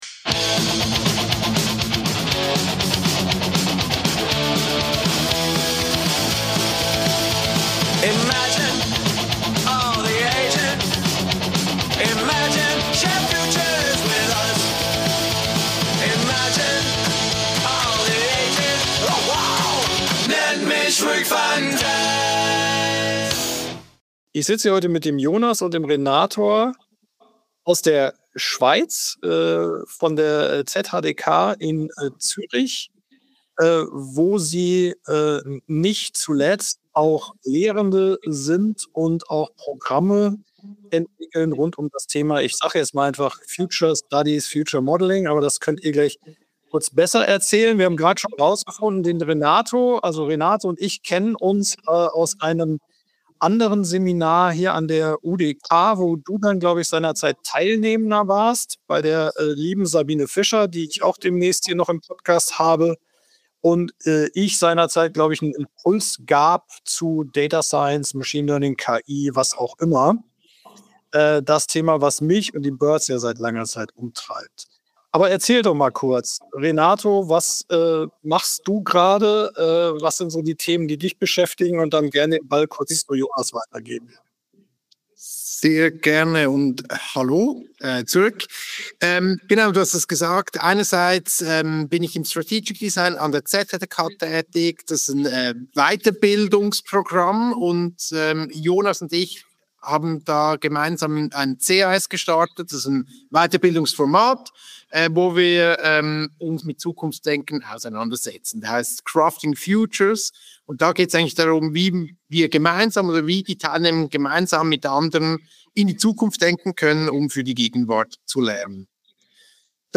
Ein Gespräch über die Angst vor der Ersetzbarkeit, die Macht körperlicher Erfahrung und die Suche nach einem neuen Fortschrittsnarrativ jenseits von ewigem Wachstum und technologischem Determinismus, in Angesicht dessen wir alle wieder Anfänger sind, die erst lernen müssen, den „Imaginations-Muskel“ wieder richtig zu trainieren.